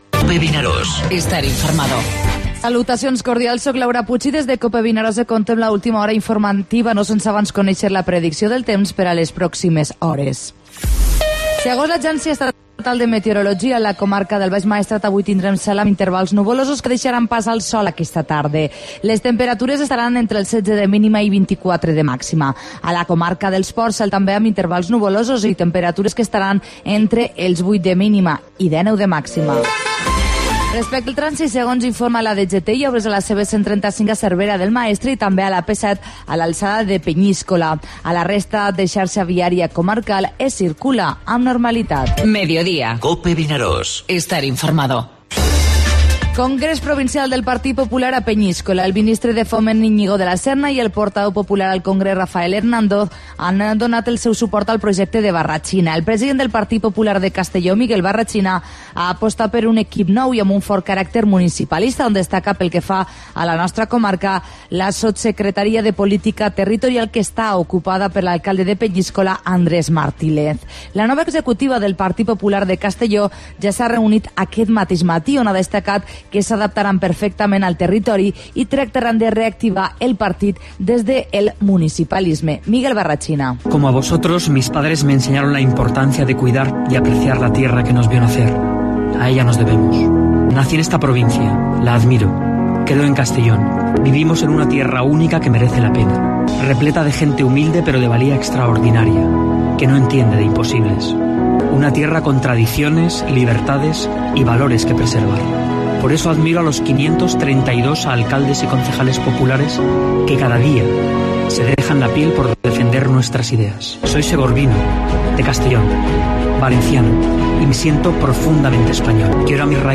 5:00H | 10 FEB 2026 | BOLETÍN